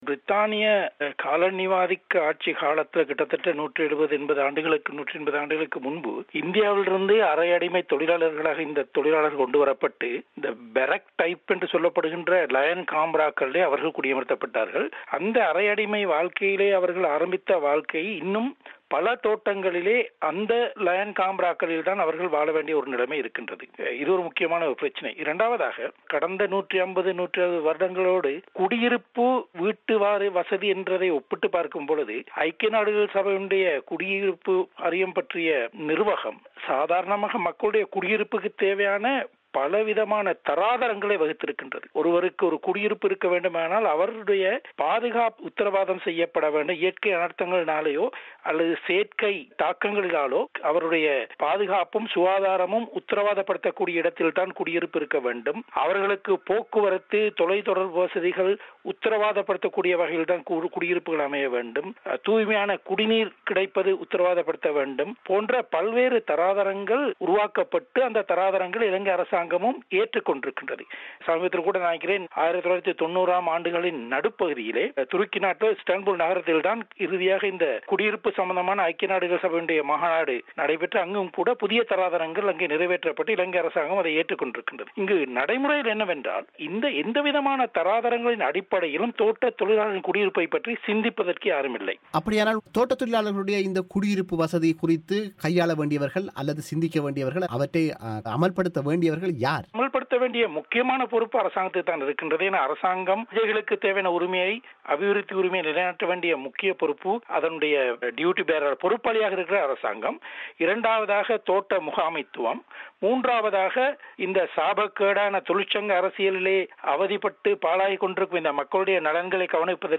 தோட்ட வீடமைப்பில் இருக்கக் கூடிய பலவீனங்கள் குறித்து அவர் பிபிசிக்கு வழங்கிய முழுமையான செவ்வியை நேயர்கள் இங்கு கேட்கலாம்.